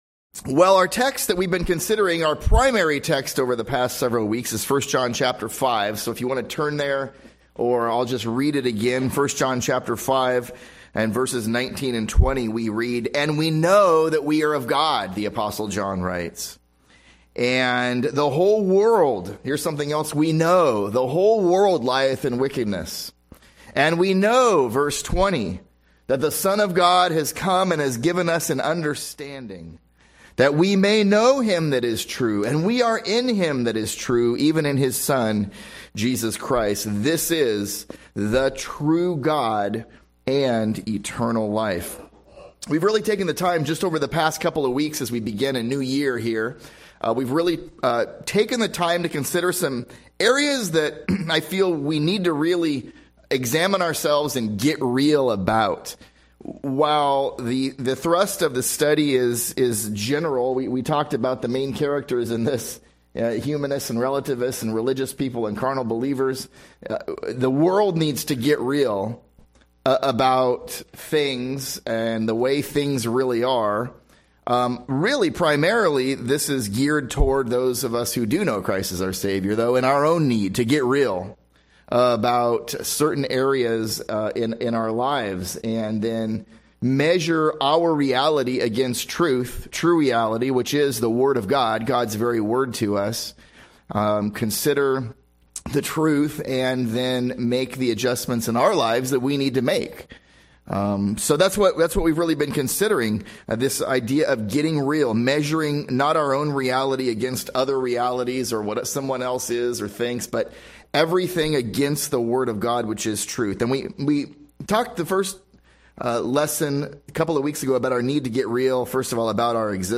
1 John 5:19-20 [SERMON ID] 2378